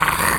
lizard_hurt_hiss_02.wav